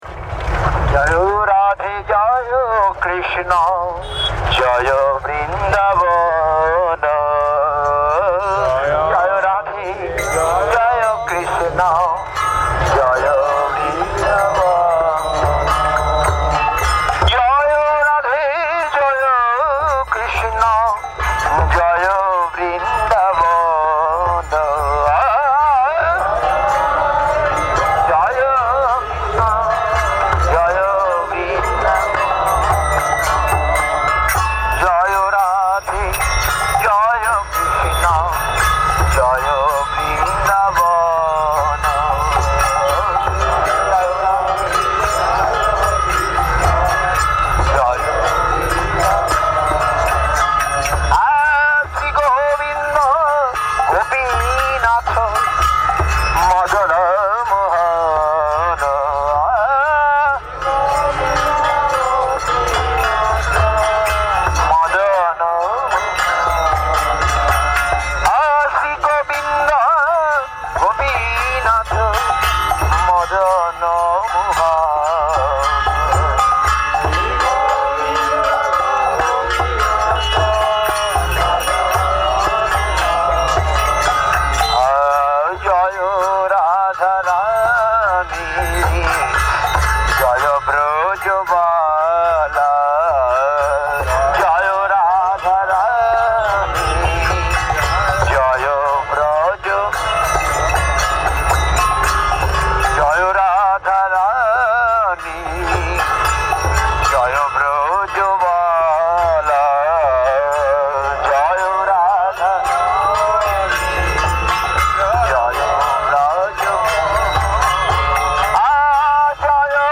Cycle: Gaura Purnima Parikrama 2012
Place: SCSMath Nabadwip
Tags: Kirttan